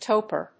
/ˈtəʊpə(英国英語)/